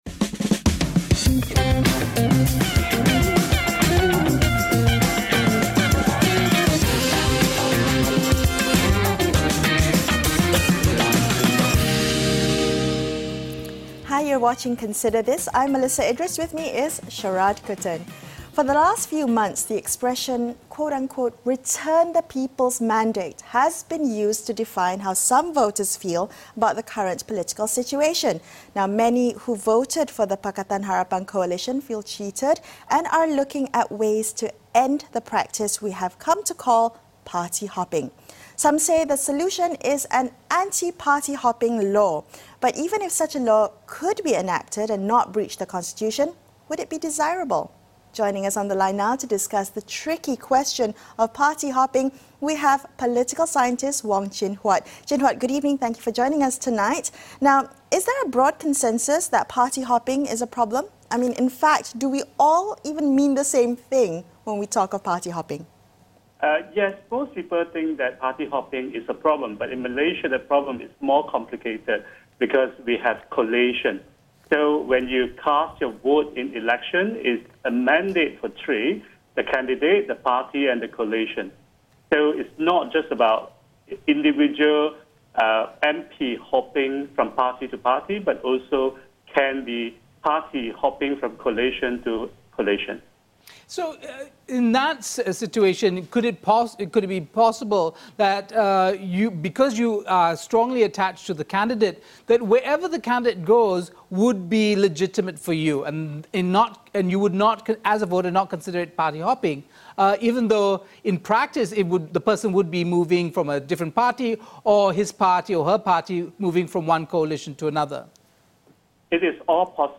political scientist